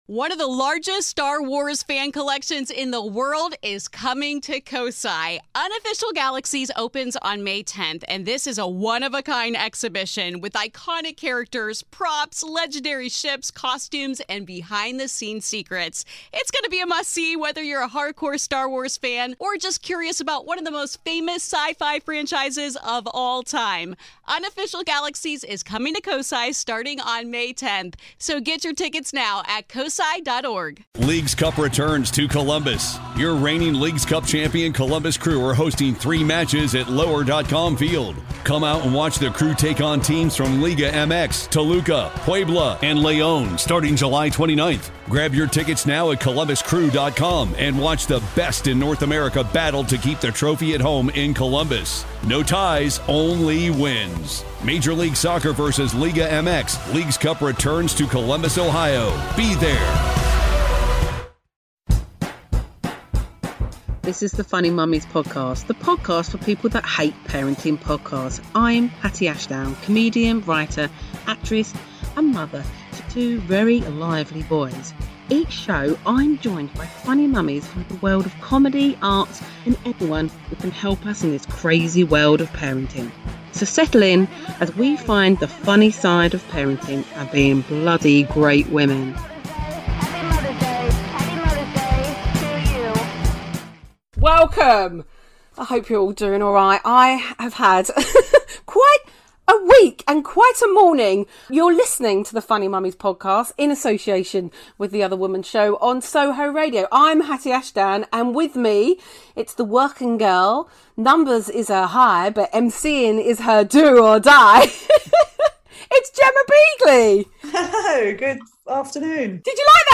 A very honest empowering funny chat about ditching doubt, dating apps, being safe, and dogs that need to leave the room.